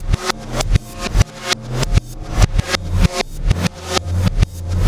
Black Hole Beat 20.wav